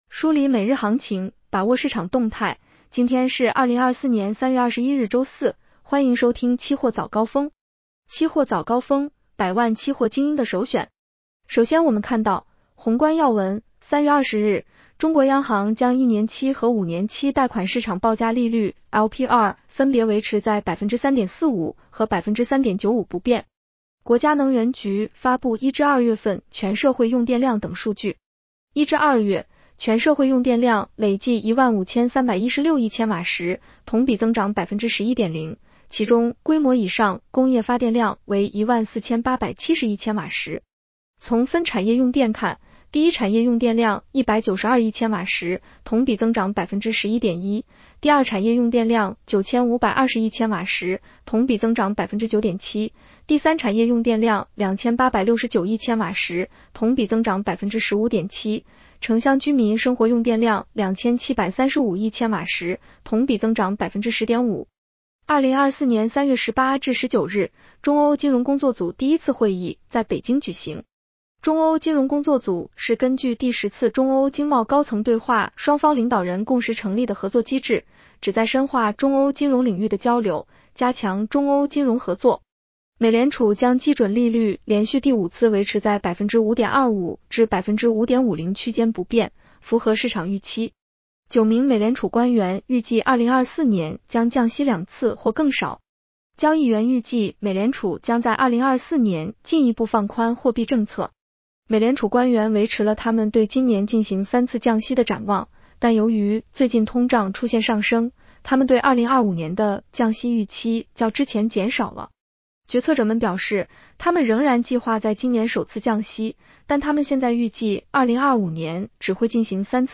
期货早高峰-音频版 女声普通话版 下载mp3 宏观要闻 1. 3月20日，中国央行将一年期和五年期贷款市场报价利率（LPR）分别 维持在3.45%和3.95%不变。